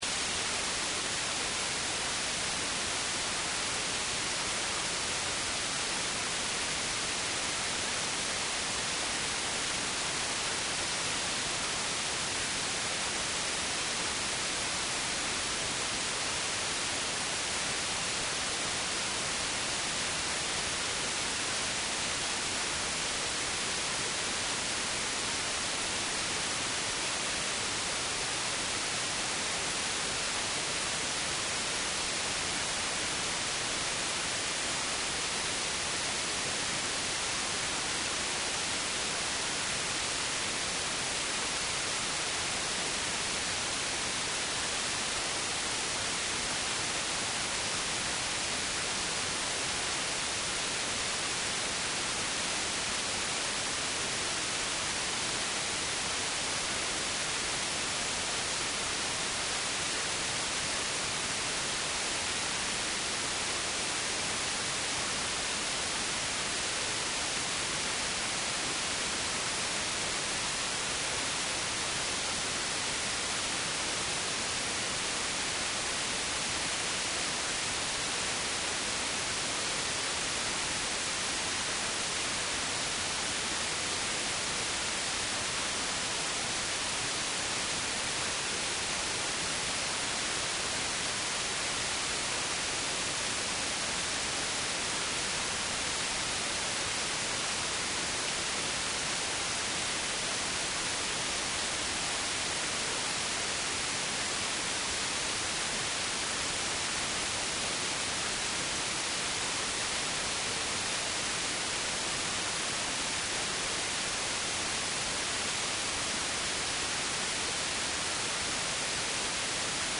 Te Ama Pasefika Health is promoting the well-being of Pacific people. Each week you’ll hear interviews with studio guests giving advice on health, education, employment and other support services that encourage wellness and foster healthy, happy lives for Pasefika people in New Zealand.